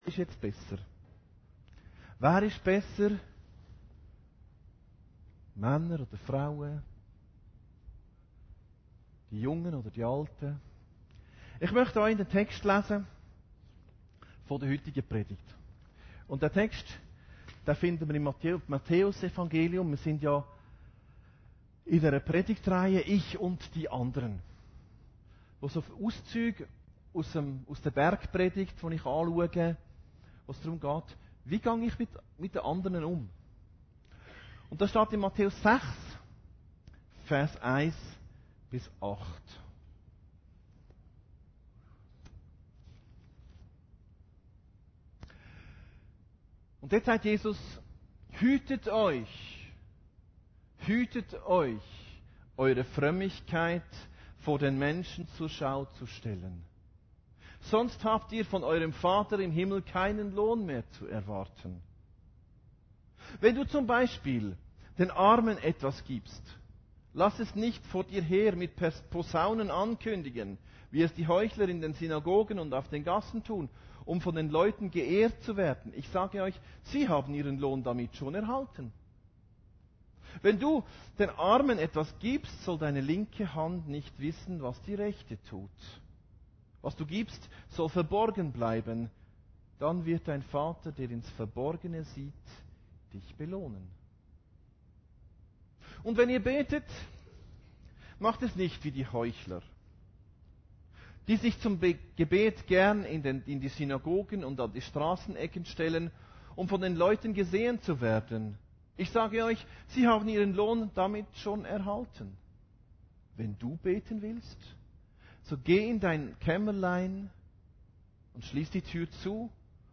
Predigten Heilsarmee Aargau Süd – Ich und die Anderen Konkurenz